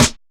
DrSnare13.wav